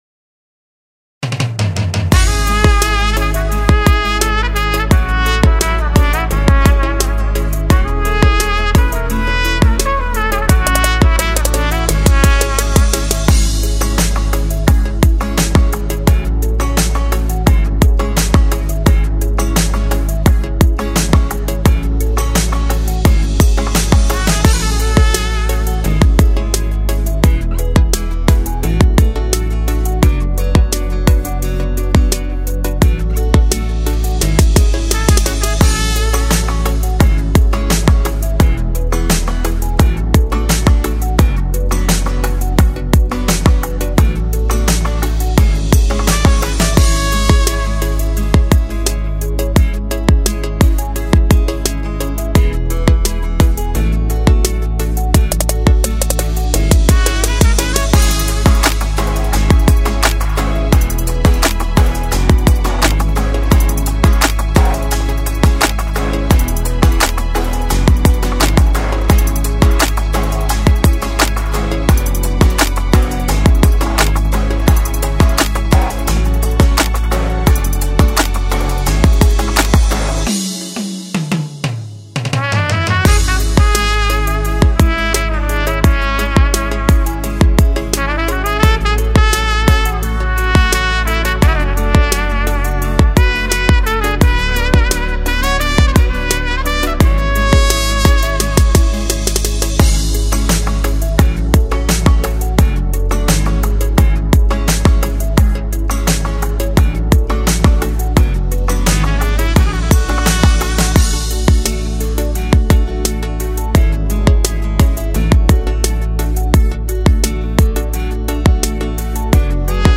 بی‌کلام
در مسجد مقدس جمکران انجام شده است